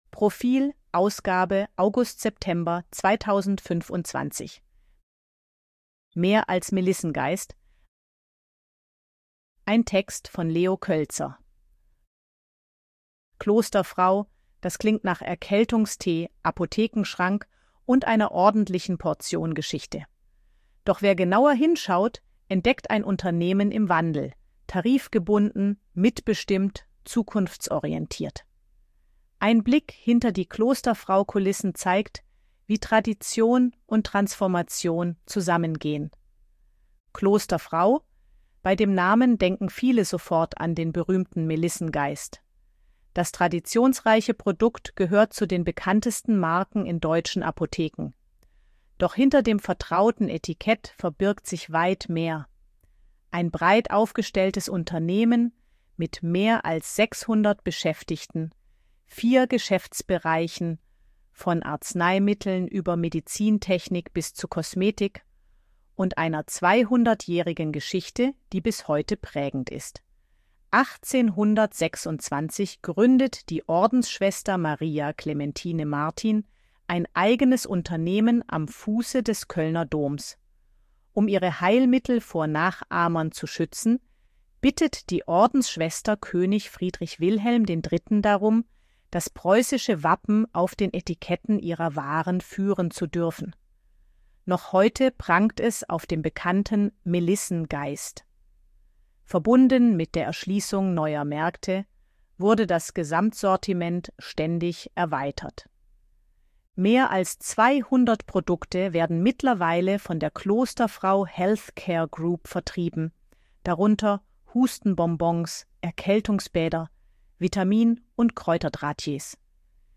ElevenLabs_252_KI_Stimme_Frau_AG-Check.ogg